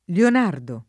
Lionardo [ lion # rdo ]